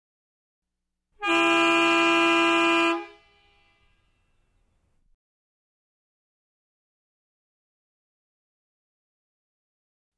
Звуки тягача
Звук гудка грузовика - сигнал